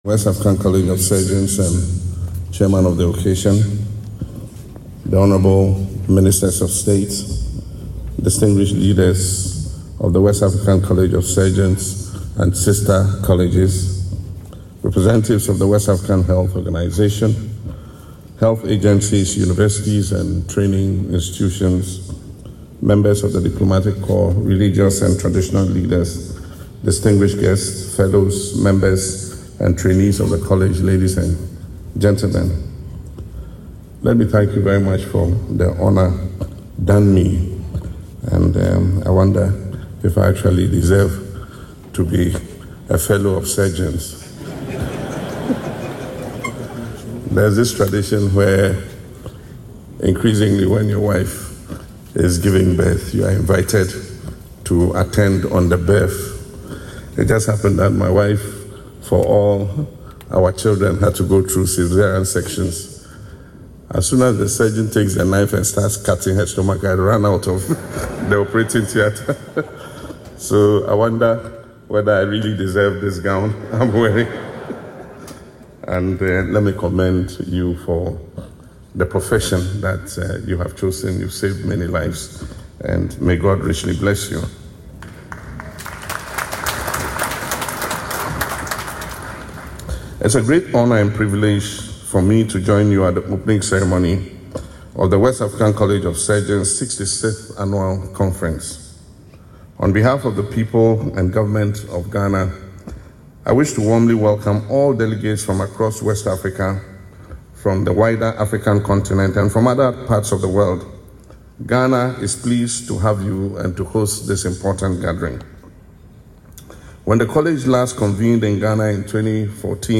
Speaking at the 66th Annual Conference of the West African College of Surgeons on Monday, February 9, 2026, President Mahama underscored the critical role of timely and effective surgical care in saving lives, noting that delays in managing surgical emergencies often lead to preventable deaths and prolonged suffering.